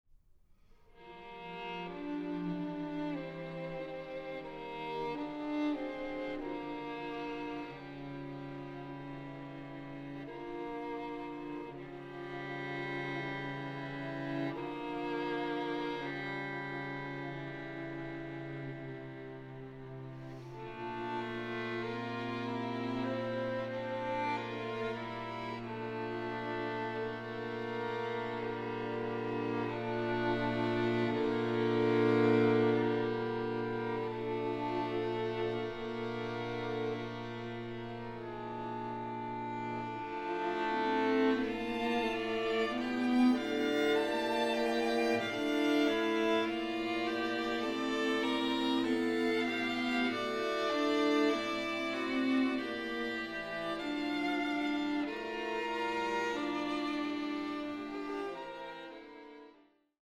String Quartet in D major